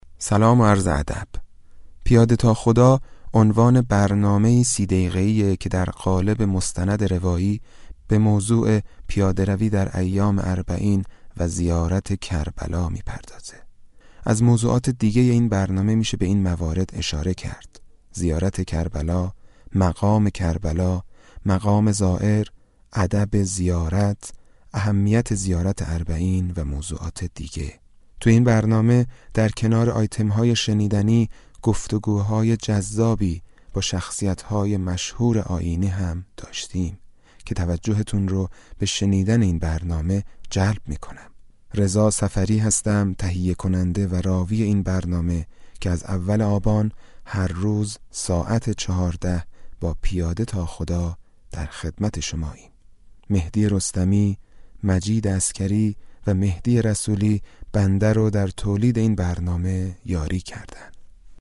موضوع ویژه ‌برنامه‌ی «پیاده تا خدا»، پیاده روی اربعین و نگاه ویژه به این حركت عظیم انسانی و تبیین علل و عوامل آن است. گفتگو با هنرمندان و شخصیت‌های مشهور آیینی، استفاده از گزارش‌های مردمی، گزیده‌ی سخنان بزرگان و قصه‌های كوتاه بر اساس روایت‌هایی درباره‌ی موضوع برنامه در قالب مستند– تركیبی– تولیدی، بخش‌های مهم ویژه ‌برنامه‌ی «پیاده تا خدا» را تشكیل می‌دهد.